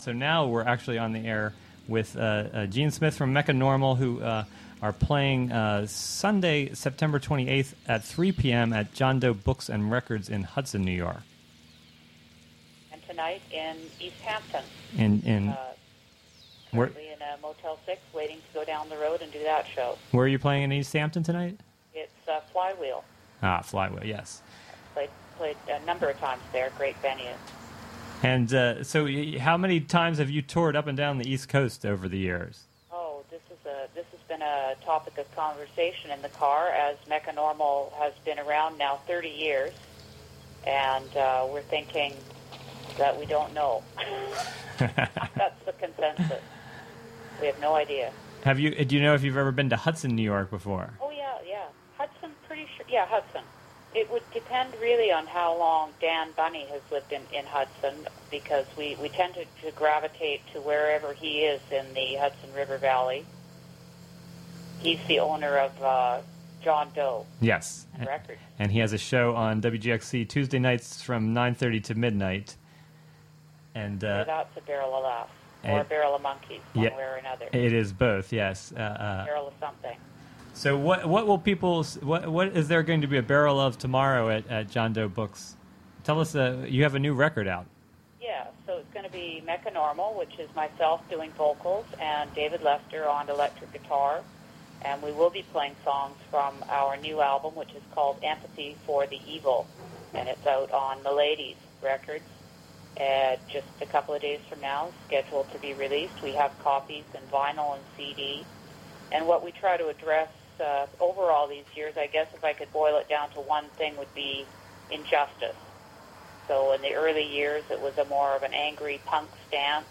Indie rock legends Mecca Normal will phone in and...